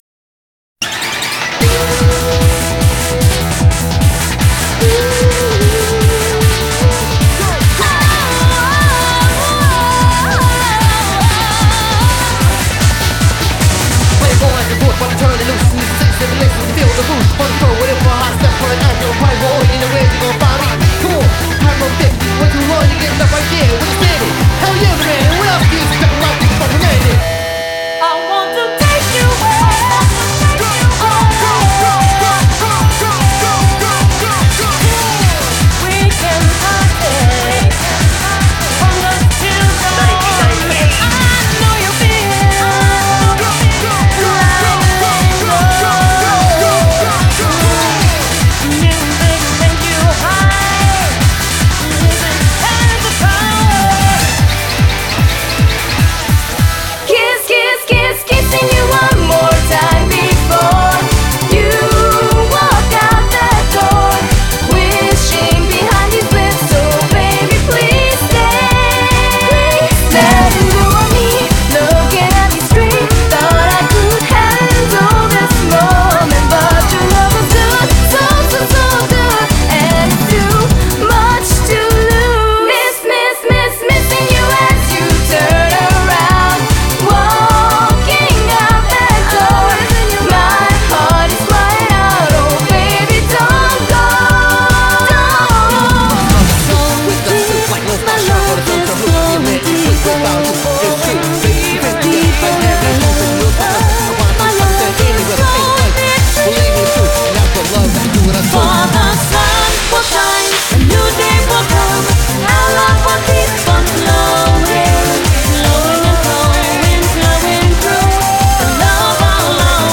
BPM83-666
Audio QualityPerfect (High Quality)
Megamix done by Me